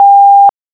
smbusy.wav